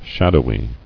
[shad·ow·y]